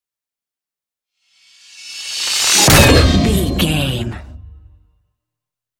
Dramatic horror metal hit large
Sound Effects
Atonal
heavy
intense
dark
aggressive